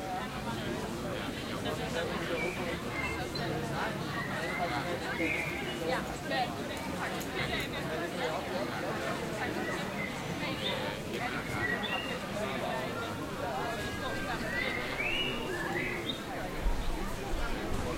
Improve city ambient sounds
cityAncient.ogg